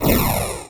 fire_a.wav